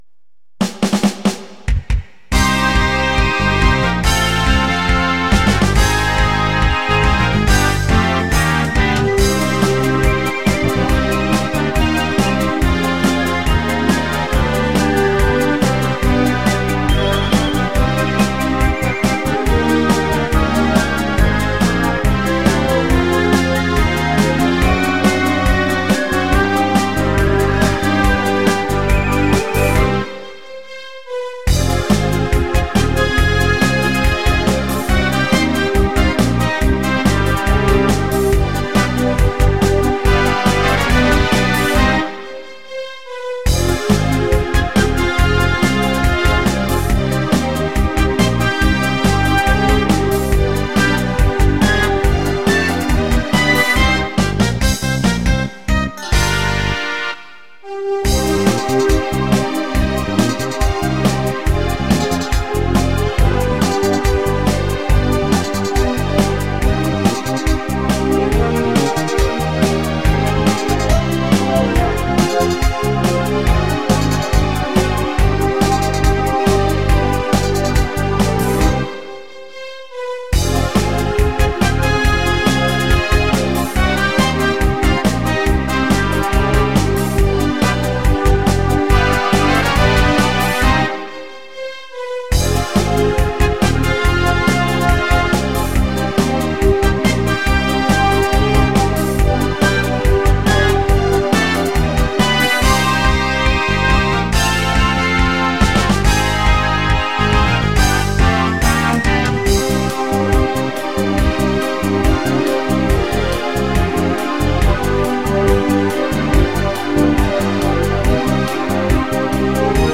Слушать или скачать минус к песне